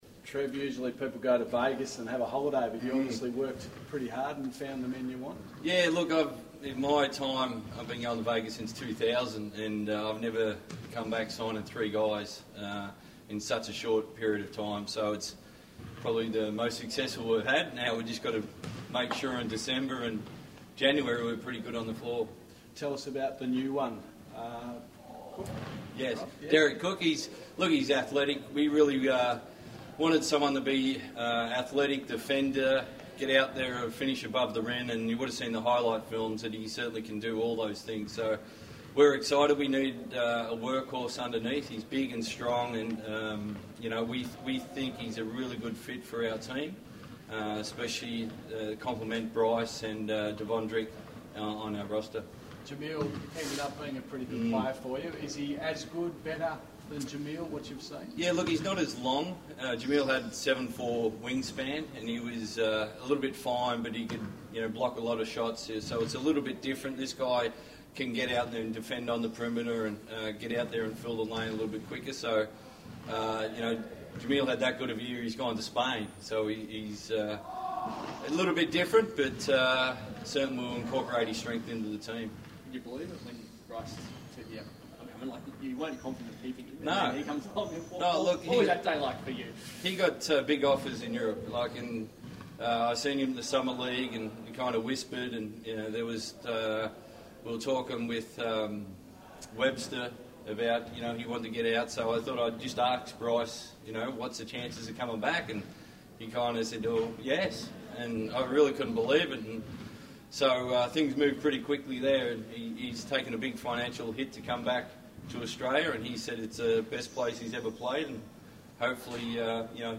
Press Conference - 27 July 2017